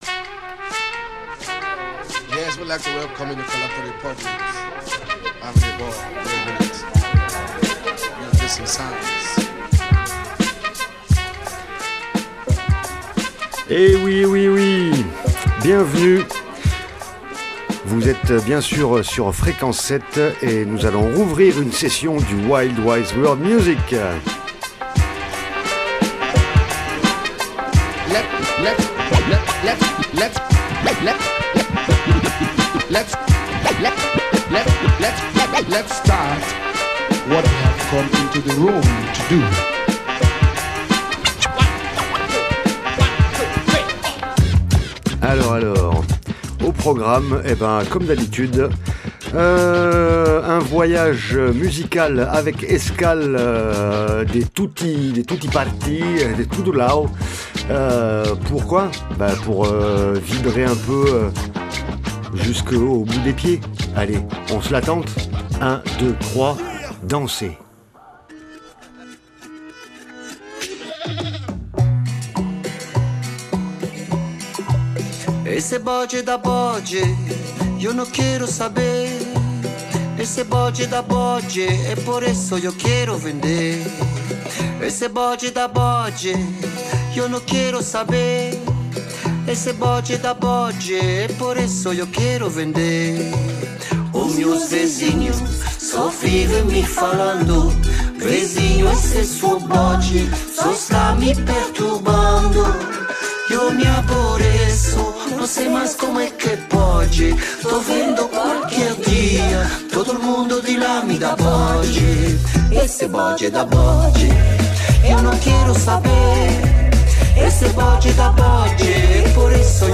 remix tribal bass
Drums n Bass remix